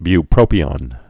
(by-prōpē-ŏn)